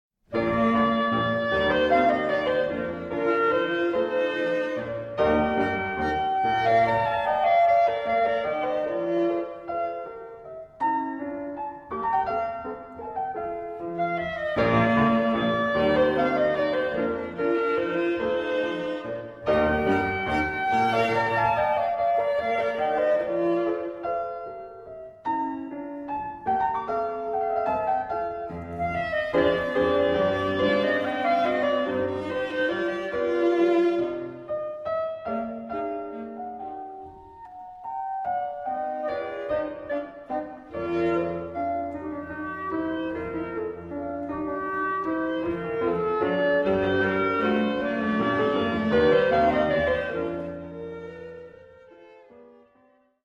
Chamber Music